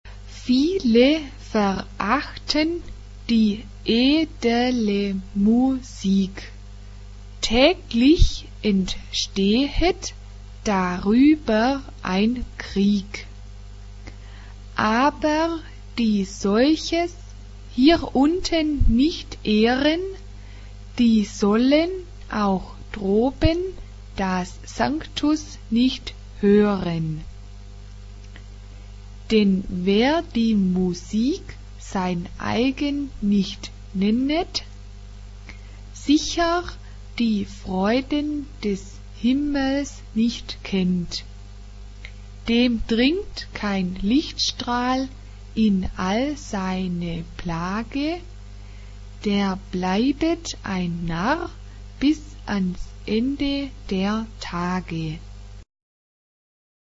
SAB (3 voix mixtes) ; Partition complète.
Tonalité : si bémol majeur